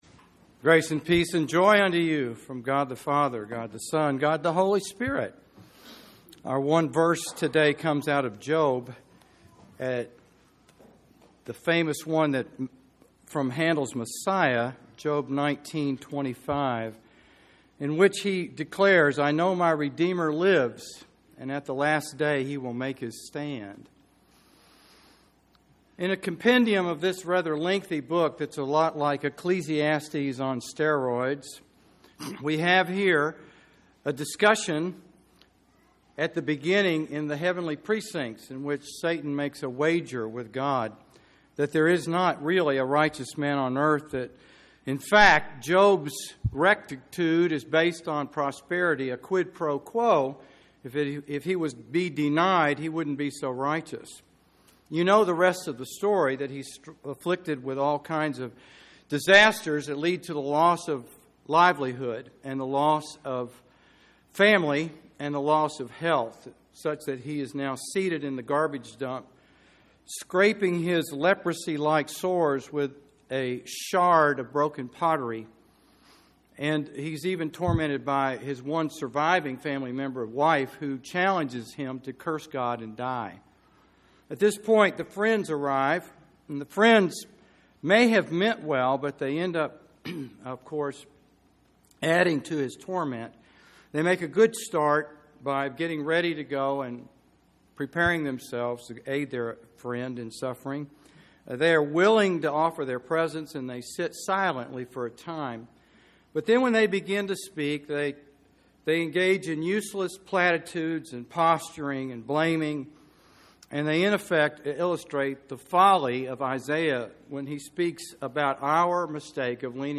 Sermon-6.13.21.mp3